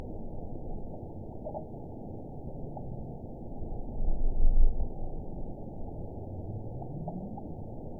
event 914328 date 05/05/22 time 00:17:46 GMT (3 years ago) score 9.57 location TSS-AB06 detected by nrw target species NRW annotations +NRW Spectrogram: Frequency (kHz) vs. Time (s) audio not available .wav